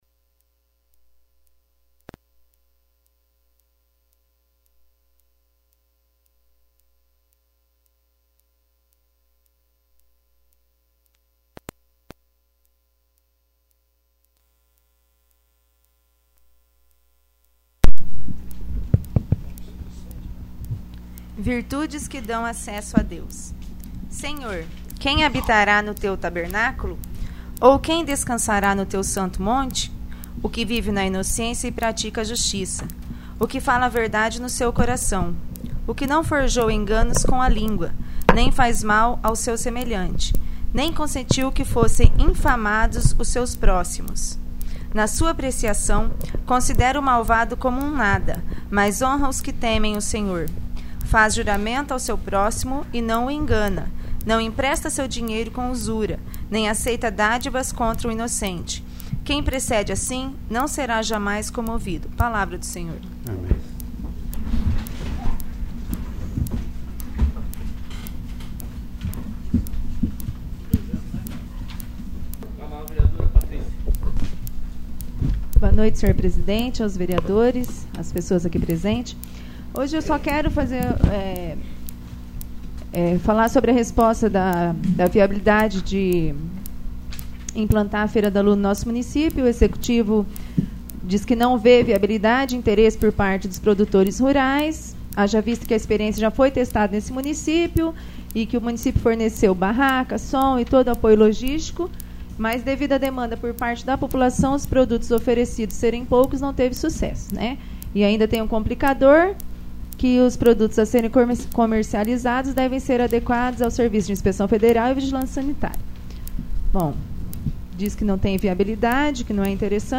34º. Sessão Ordinária